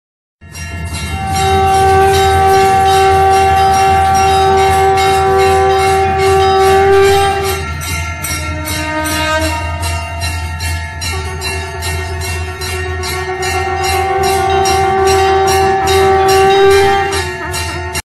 Categories Indian Festival Ringtones